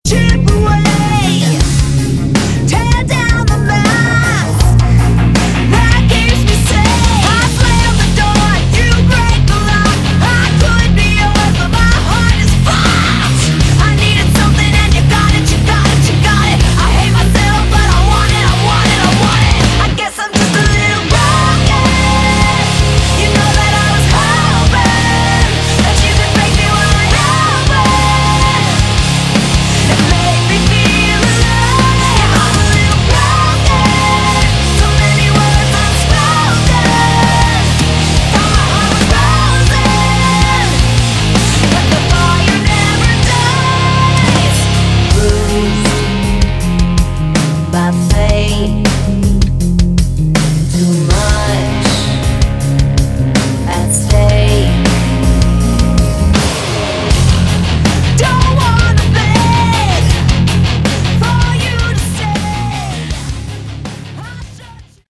Category: Modern Hard Rock
guitars
drums